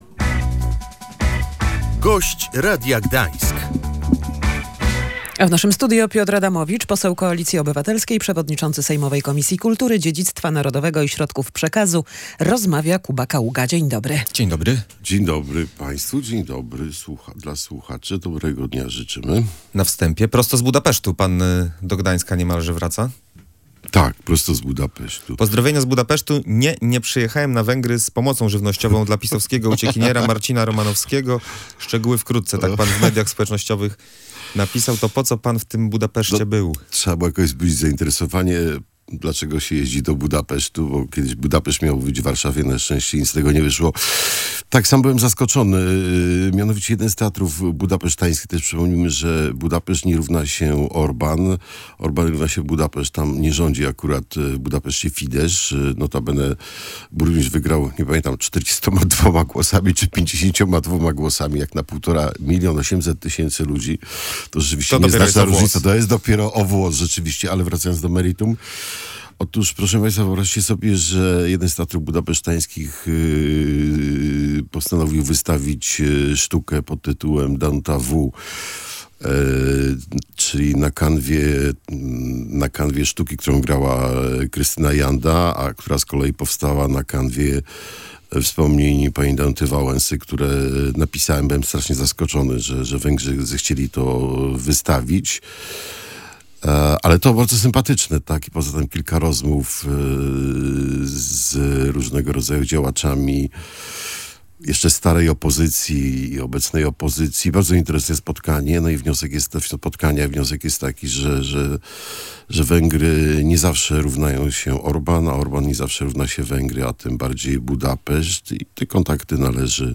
W ciągu kilkunastu dni powinna zostać upubliczniona nowa ustawa medialna – mówił w Radiu Gdańsk poseł Piotr Adamowicz, poseł Koalicji Obywatelskiej, przewodniczący sejmowej Komisji Kultury, Dziedzictwa Narodowego i Środków Przekazu.